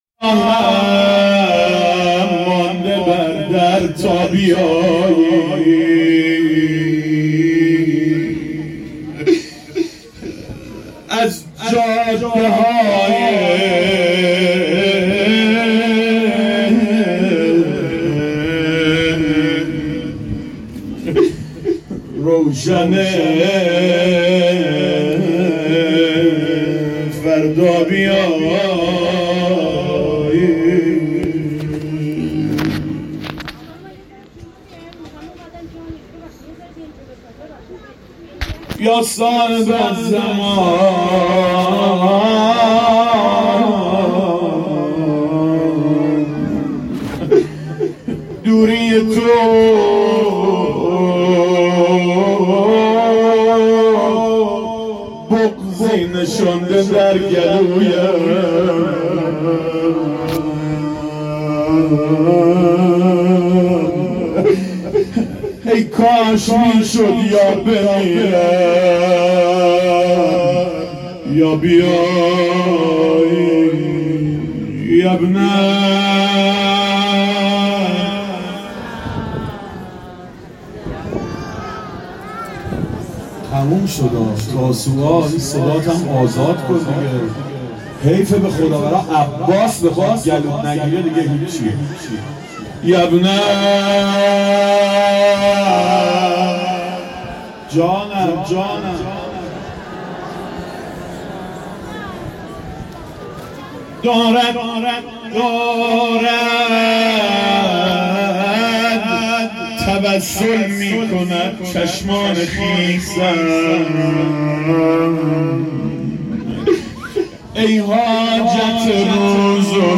حسینیه مسجد صاحب الزمان رزکان نو